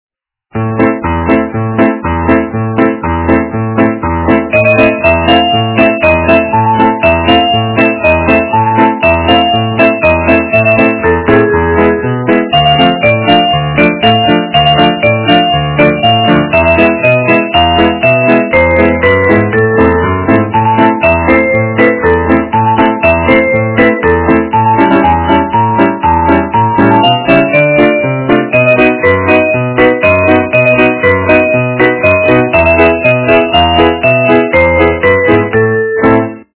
полифоническую мелодию из м.ф.